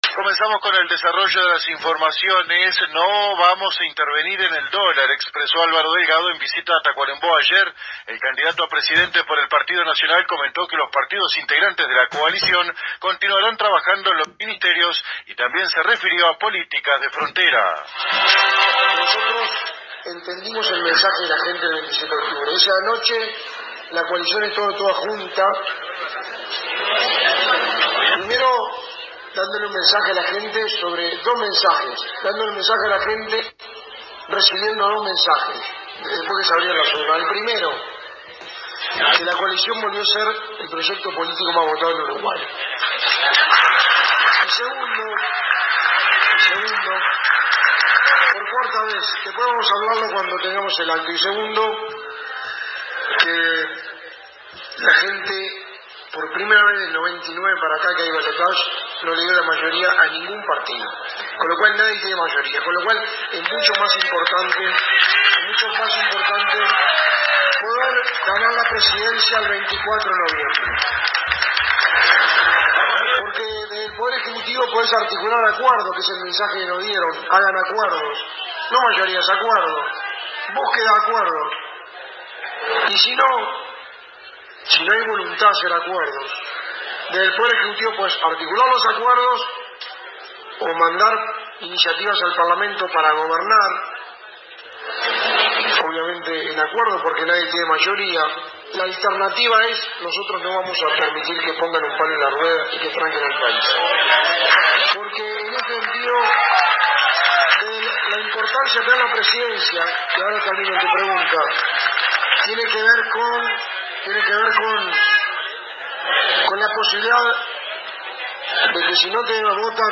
En su alocución a las personas que lo esperaron varias horas en el Salón de la Sociedad Criolla Patria y Tradición, -debía presentarse a las 18:30 horas y llegó procedente de Melo a las 22:00-, expresó que se van a generar mecanismos de inversión para que más empresas se instalen en el interior del país, aplicando incentivos que ya existen en la Ley de Inversiones, pero también mejorando la conectividad y las rutas para impulsar la competitividad, e hizo referencia a las mejoras que ha realizado el actual gobierno en Ruta 6 que comunica el interior del departamento.
Escuche parte de su intervención aquí: